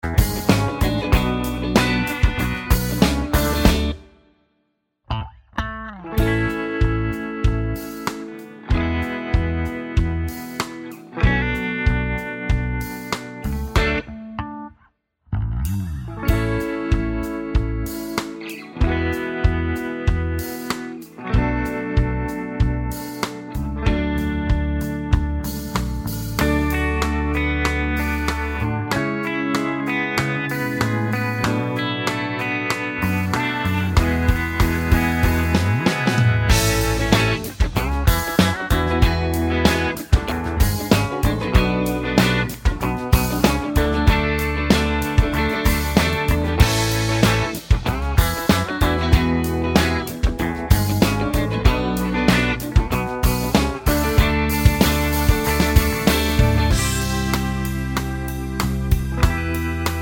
Live Lounge Version Pop (2010s) 2:59 Buy £1.50